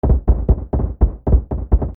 階段に身体が連続でぶつかる 03-02
/ H｜バトル・武器・破壊 / H-90 ｜その他材質